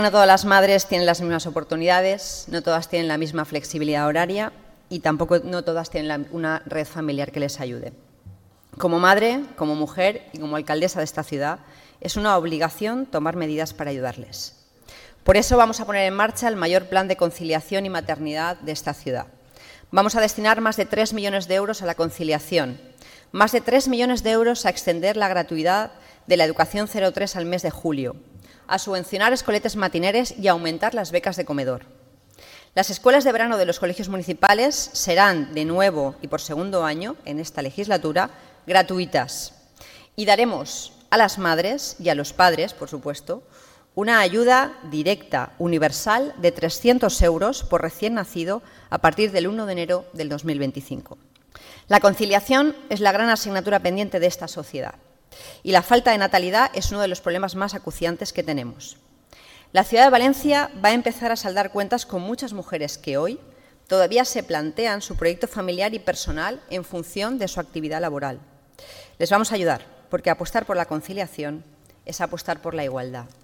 La alcaldesa María José Catalá ha terminado su discurso en el Debate del Estado de la Ciudad con un asunto que ha considerado “capital para el municipalismo”, la reforma de la financiación local
María José Catalá. Debate estado ciudad. Conciliación